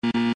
wrong.mp3